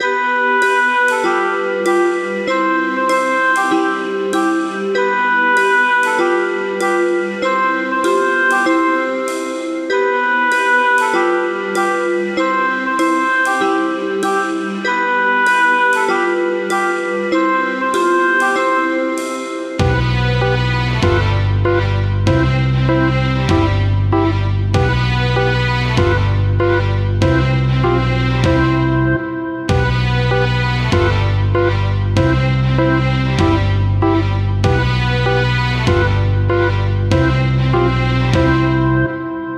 《猫砂を掘る》フリー効果音
猫砂を掘り返す時の効果音。猫砂に限らず砂を掘るような音として使えそう。